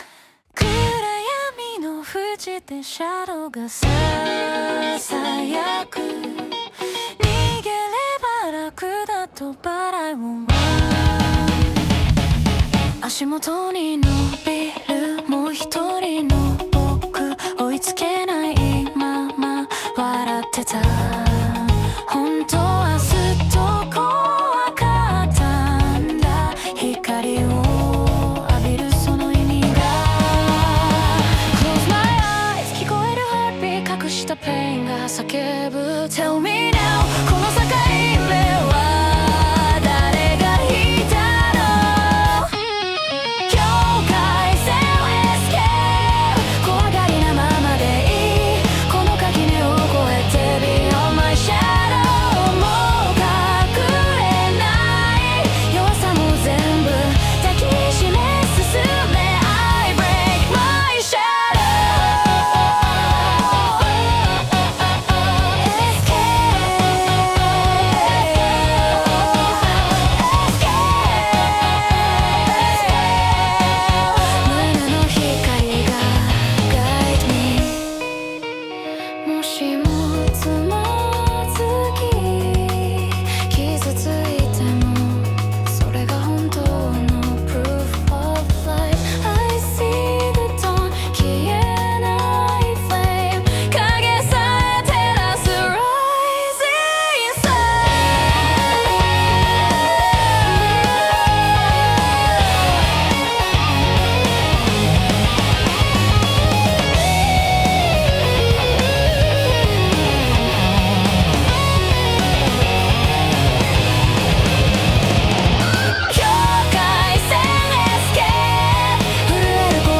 女性ボーカル
イメージ：J-ROCK,女性ボーカル,男性ラップ,かっこいい,クール,スタイリッシュ,複雑,シューゲイザー